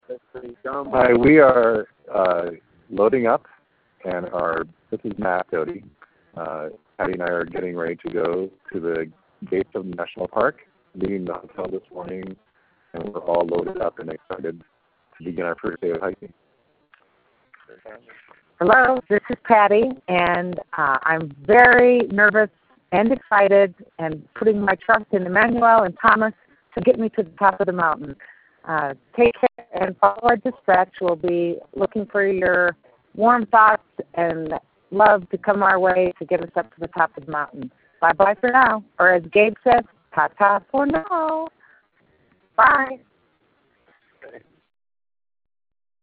Kilimanjaro Expedition Dispatch